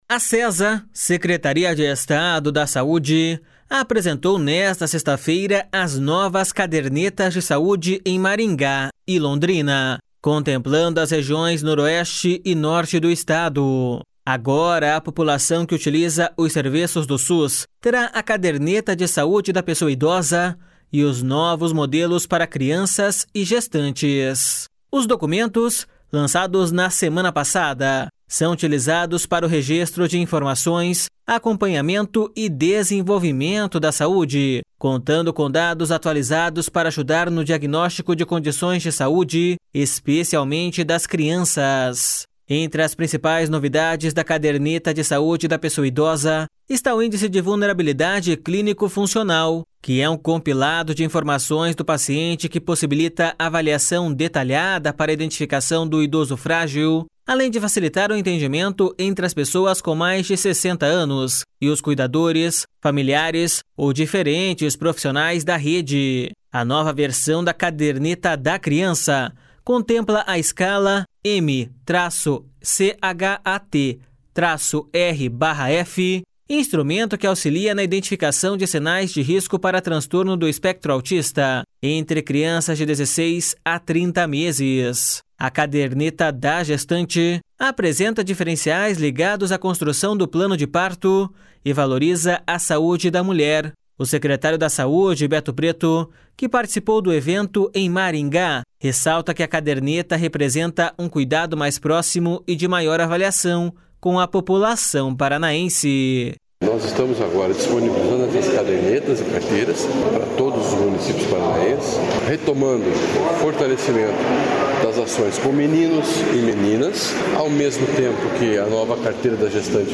O secretário da Saúde, Beto Preto, que participou do evento em Maringá, ressalta que a caderneta representa um cuidado mais próximo e de maior avaliação com a população paranaense.// SONORA BETO PRETO.//